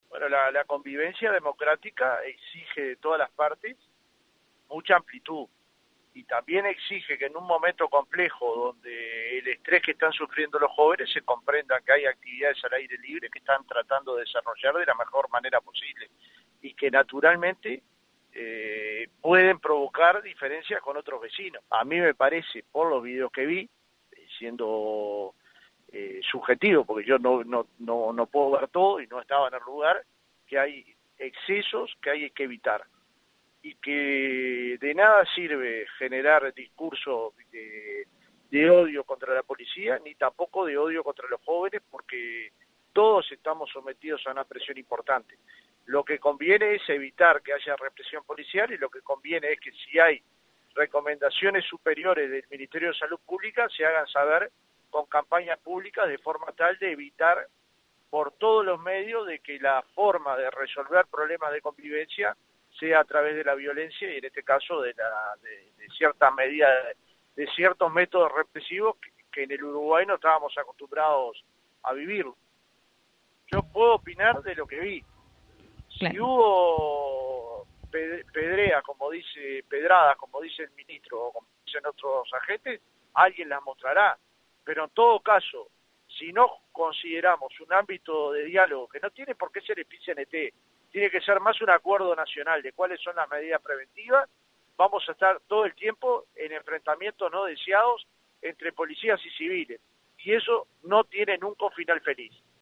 Fernando Pereira, presidente del Pit-Cnt, en diálogo con 970 Noticias se refirió al accionar policial en la plaza Seregni.